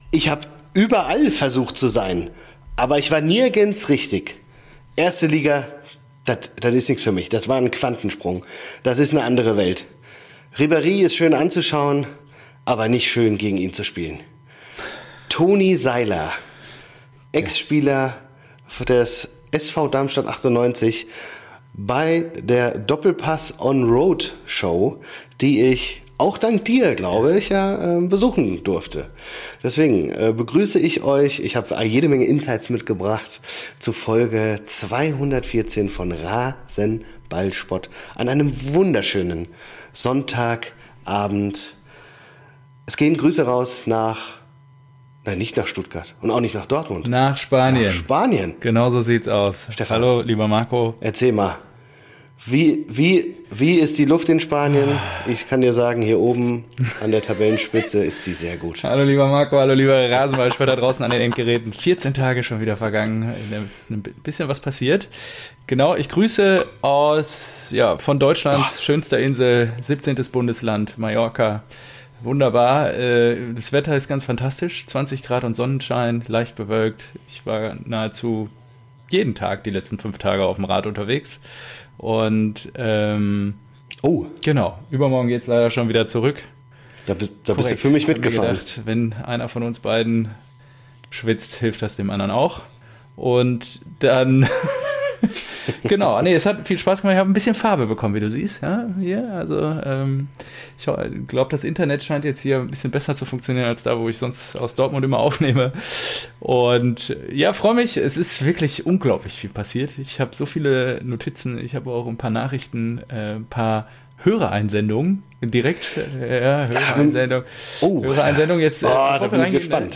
Ein BVB-Fan quatscht mit seinem SGE-Kumpel. Der Podcast für alle Vereine, die vor 2009 gegründet wurden.